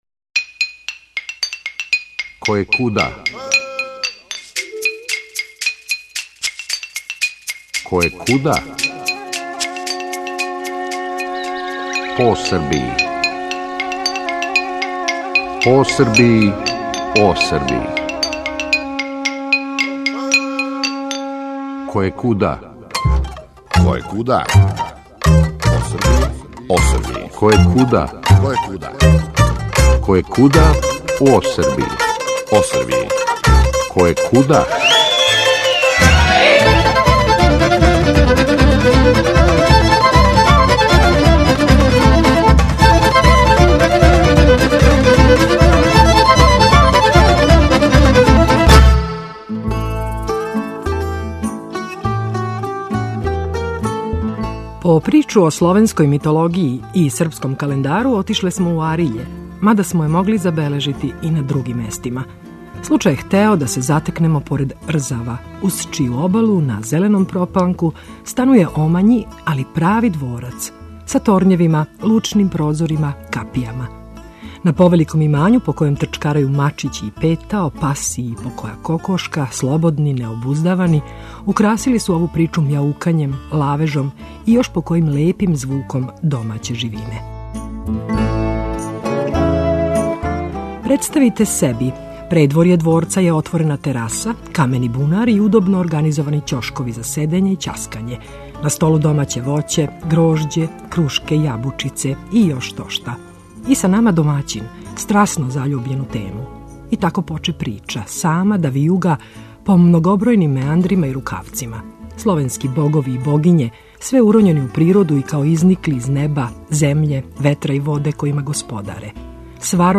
По причу о словенској митологији и српском календару отишле смо у Ариље, мада смо је могли забележити и на другим местима. Случај је хтео да се затекнемо поред Рзава, уз чију обалу, на зеленом пропланку станује омањи, али прави дворац... са торњевима, лучним прозорима, капијама... на повеликом имању по којем трчкарају мачићи и петао, пас и покоја кокошка – слободни, необуздавани, украсили су ову причу мјаукањем, лавежом и још покојим лепим звуком домаће живине.